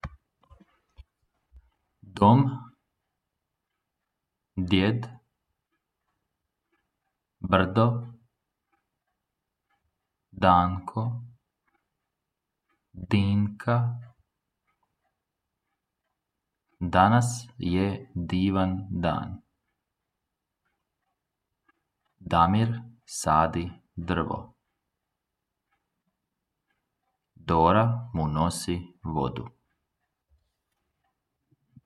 Poslušaj diktat!